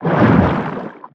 Sfx_creature_pinnacarid_swim_slow_04.ogg